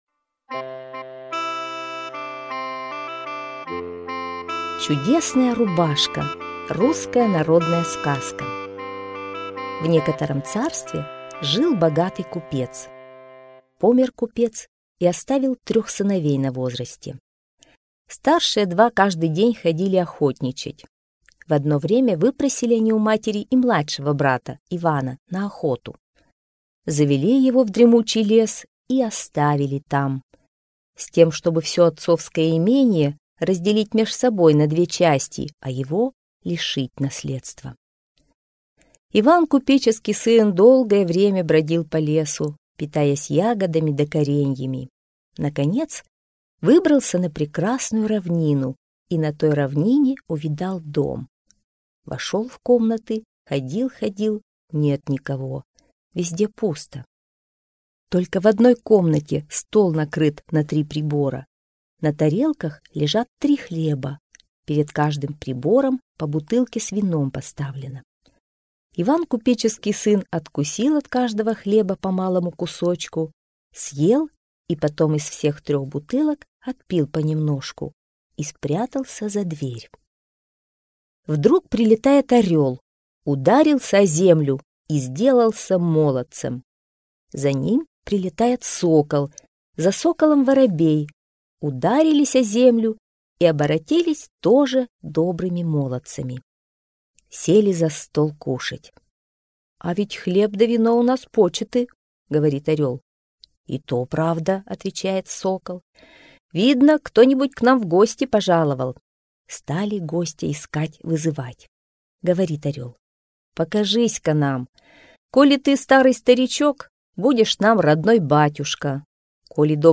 Чудесная рубашка - русская народная аудиосказка - слушать онлайн